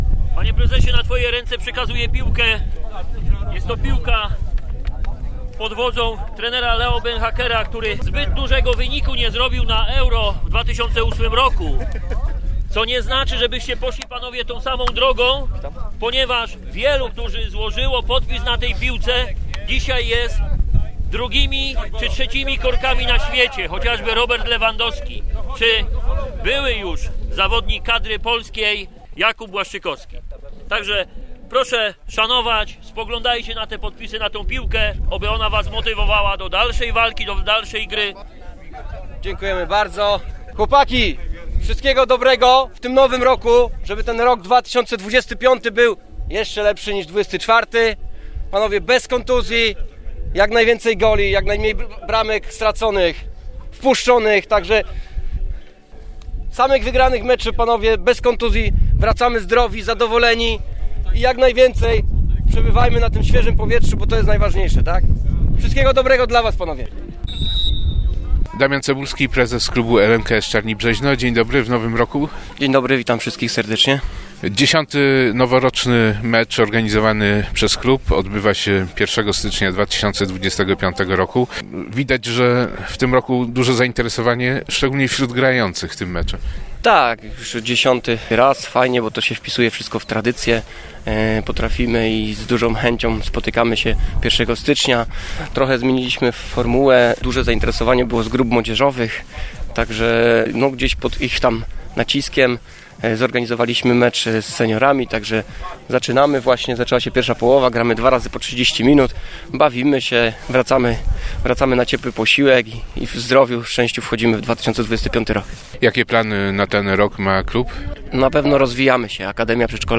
Piłkarze drużyn Seniorów i Juniorów klubu LMKS Czarni Brzeźno spotkali się na meczu noworocznym pierwszego stycznia 2025 roku.
Mimo niewysokiej temperatury i silnego wiatru, zmagania drużyn obserwowała grupka kibiców.